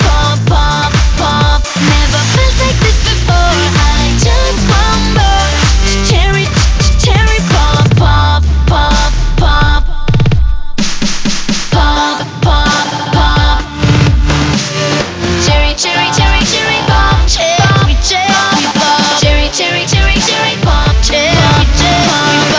Reduced quality: Yes